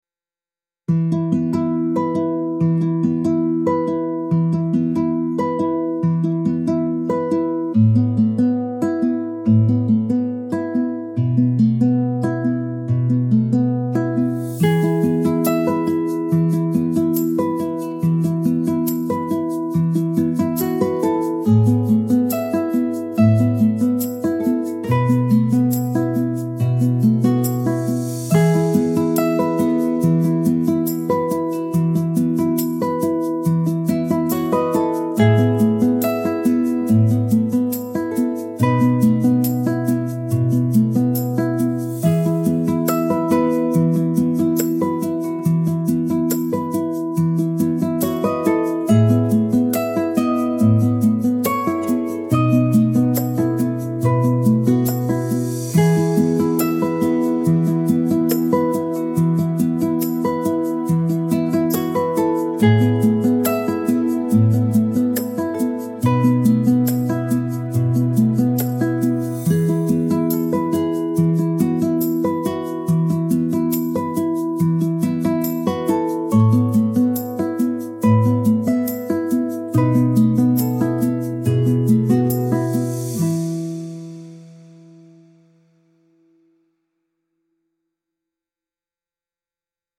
understated ambient acoustic music with gentle guitar and soft percussion